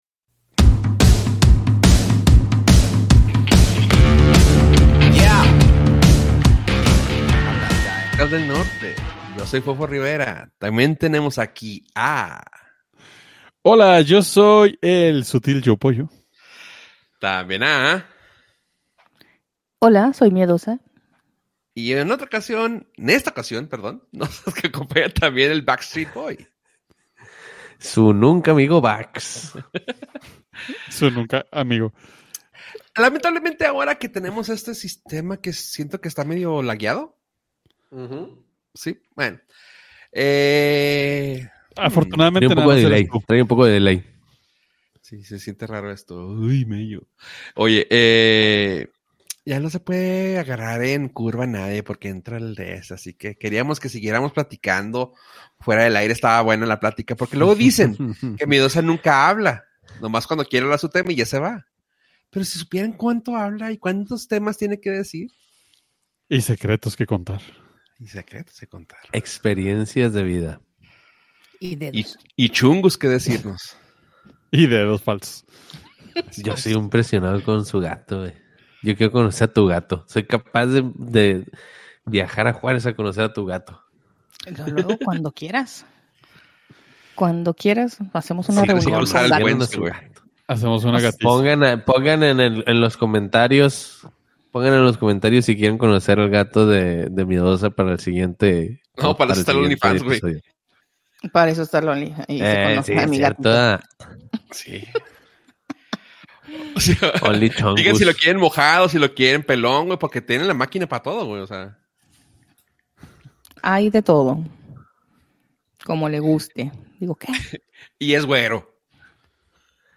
Delay, risas y ajustes técnicos incluidos.